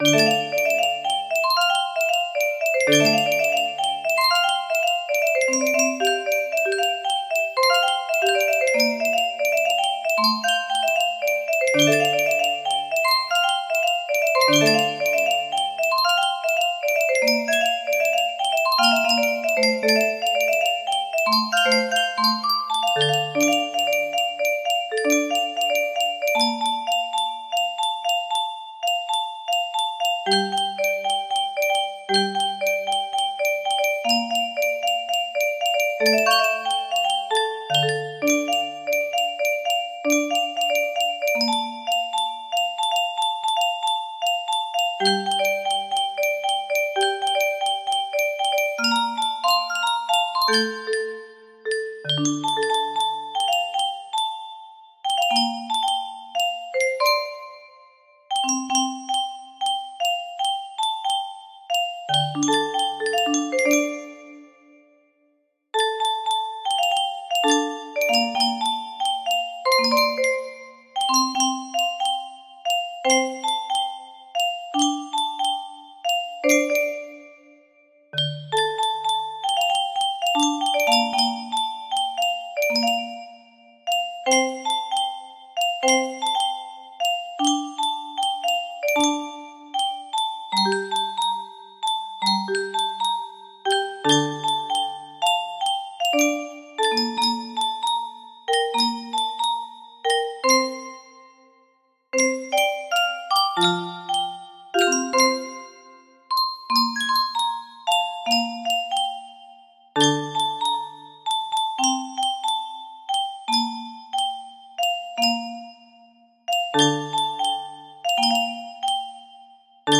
Full range 60
(My Musicbox Arrangement)